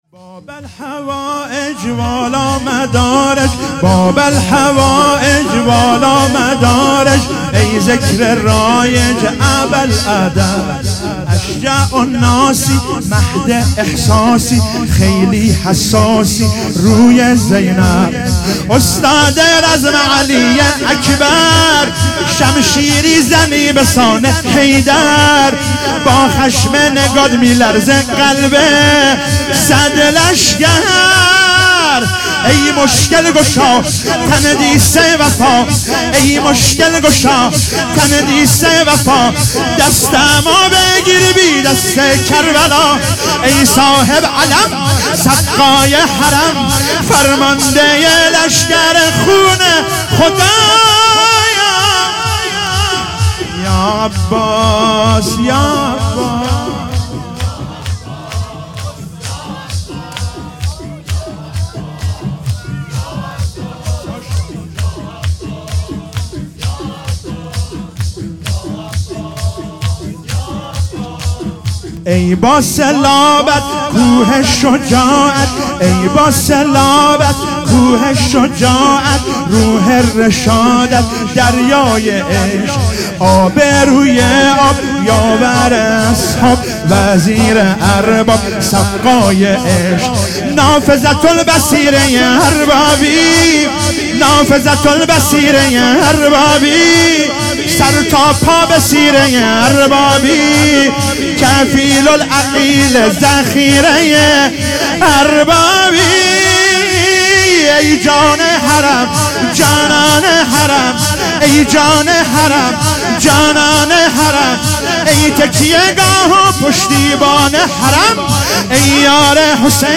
شب چهارم محرم97 - شور - باب الحوائج والا مدارج